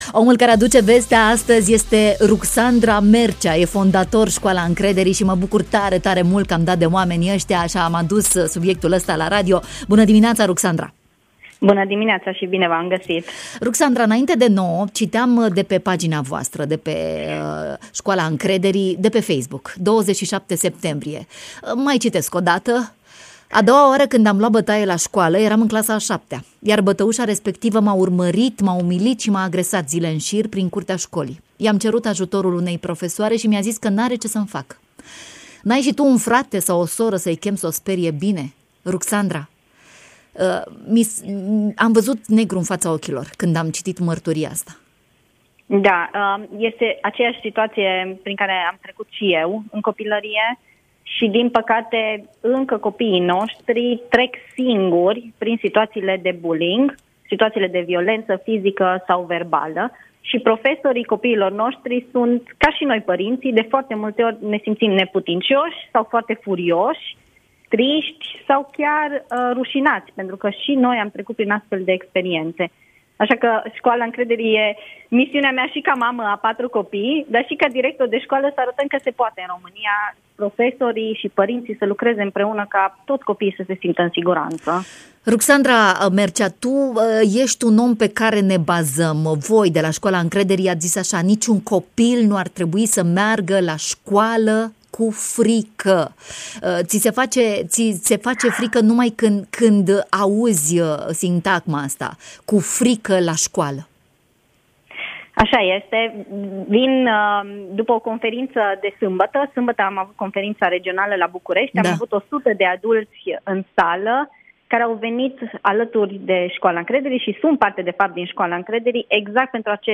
Despre libertate, dezvoltare și curaj, un dialog marca Radio Romania Iași și Școala Încrederii, mai jos: Share pe Facebook Share pe Whatsapp Share pe X Etichete: